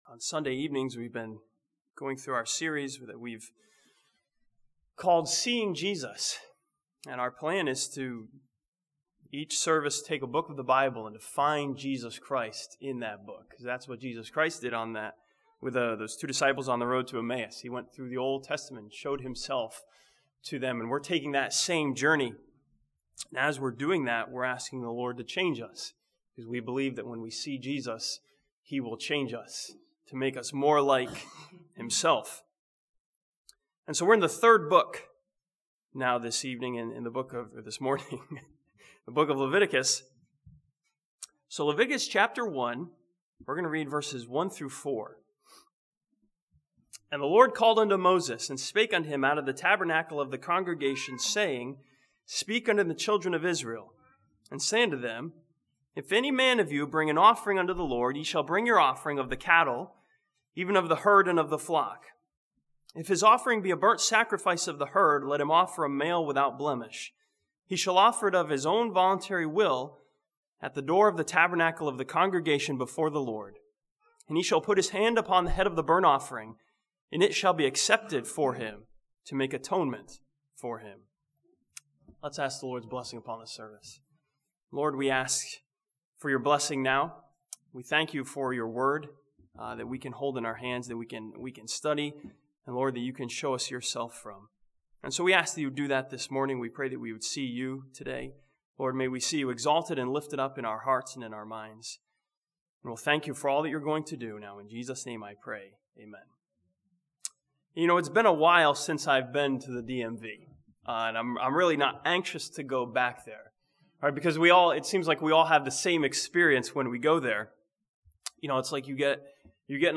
This sermon from Leviticus chapter 1 challenges believers to see Jesus as their sinless, sin-bearing, accepted sacrifice.